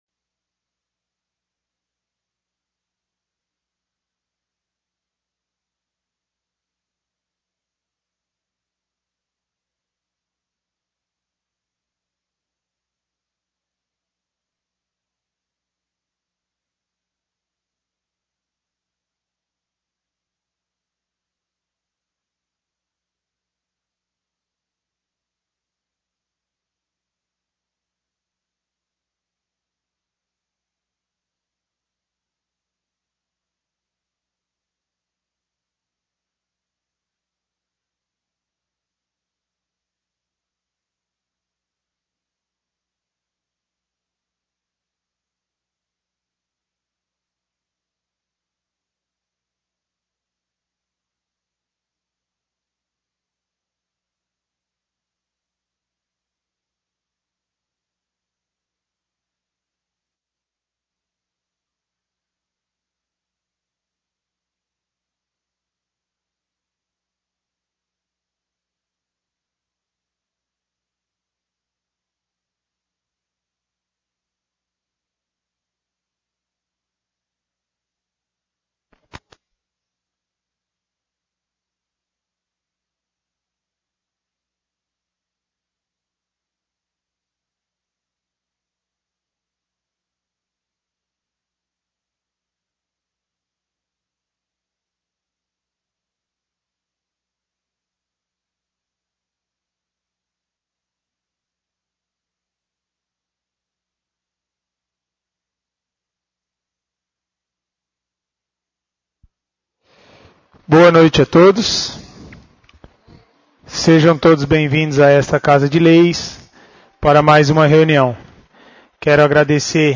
1° sessão ordinária